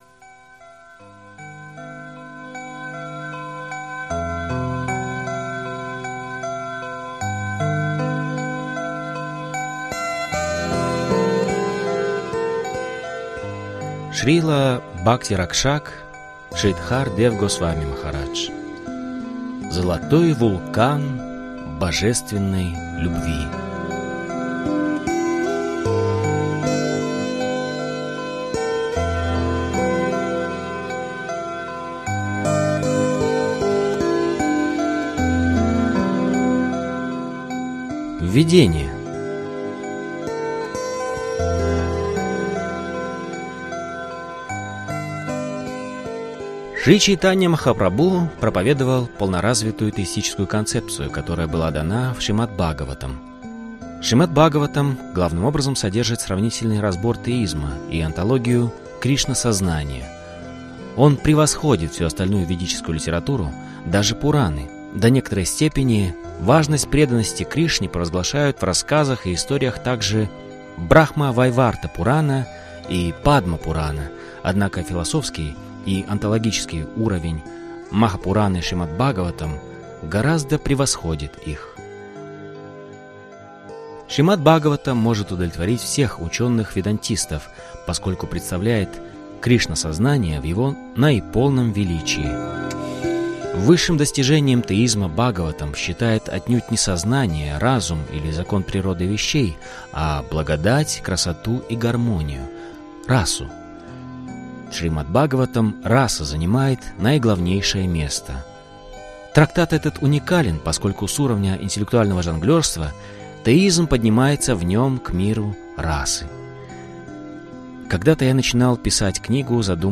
Аудиокнига Золотой вулкан божественной любви | Библиотека аудиокниг